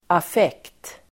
Uttal: [af'ek:t]